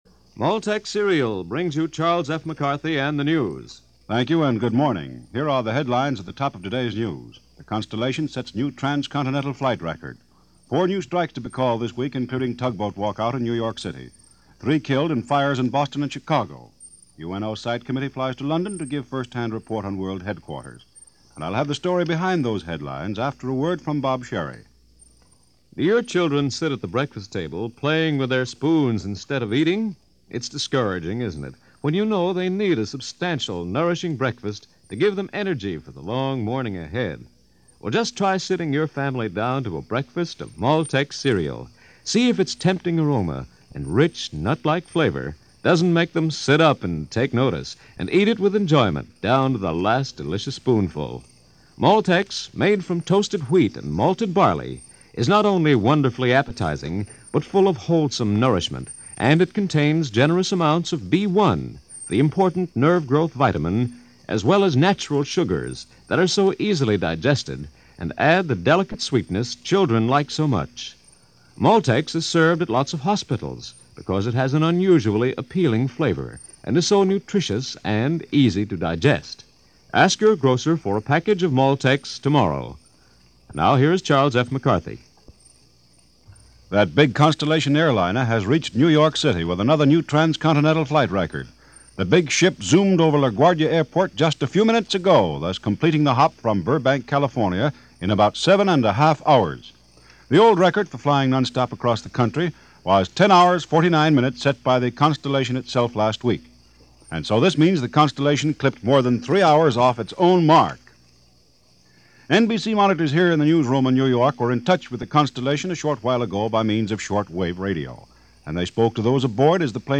Air Travel: The Bigger, Faster, Brighter Future . . . Of A Sort - February 3, 1946 this and other news from NBC Radio.
– News of the Day – February 3, 1946 – Gordon Skene Sound Collection –